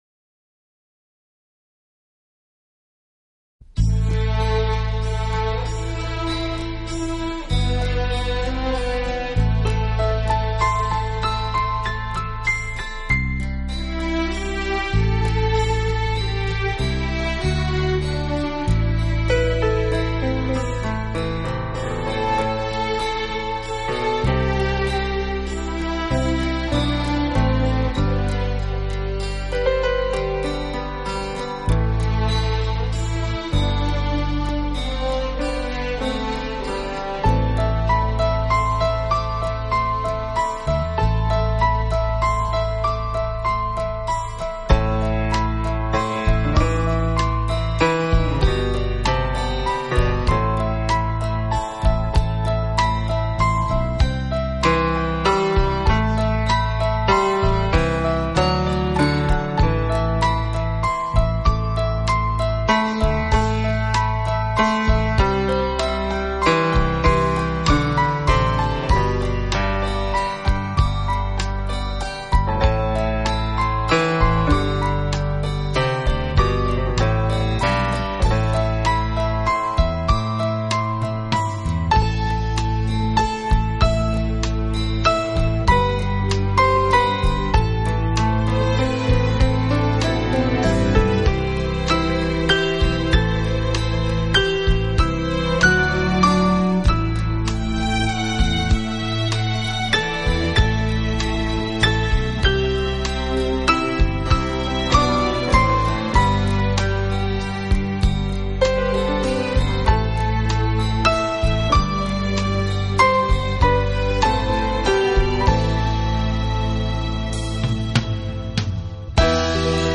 纯美的钢琴声、诉尽无限的爱恋：优雅的钢琴演奏，
港台电影钢琴配曲